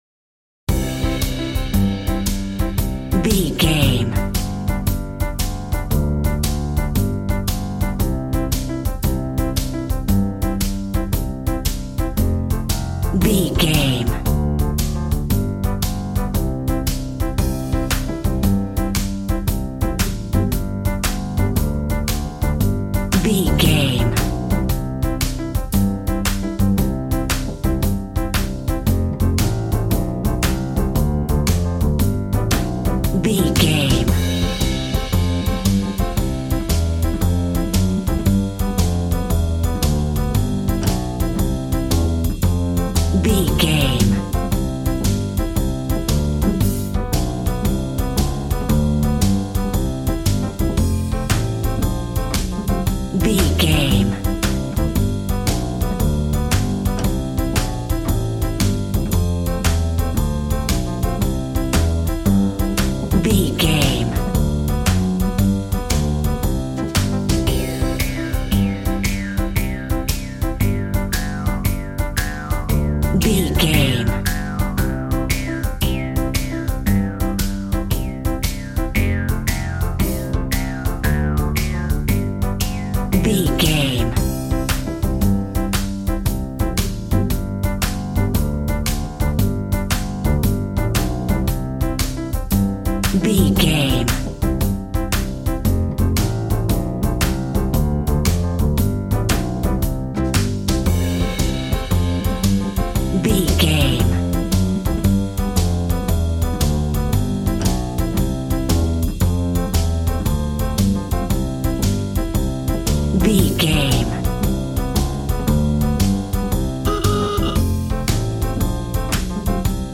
Ionian/Major
cheerful/happy
electric piano
electric guitar
drum machine